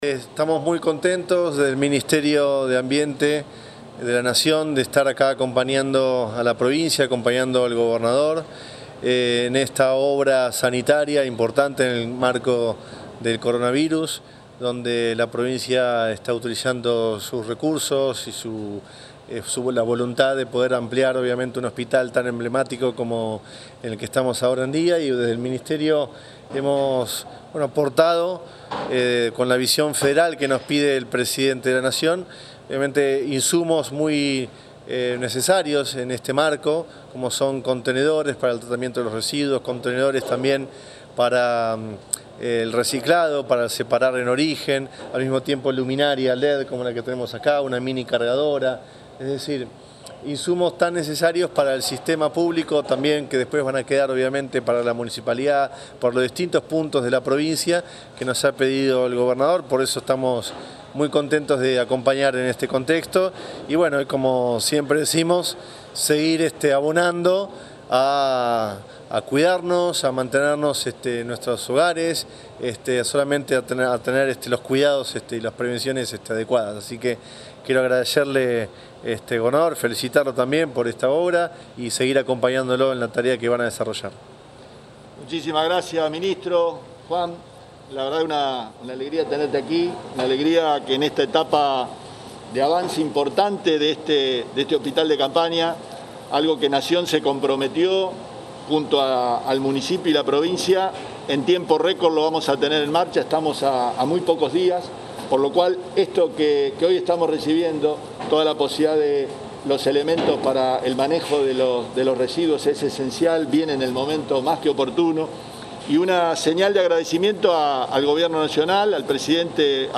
El gobernador visitó el hospital modular de Granadero Baigorria junto al ministro de Ambiente y Desarrollo Sostenible de Nación, Juan Cabandié.
Audio Omar Perotti y Juan Cabandie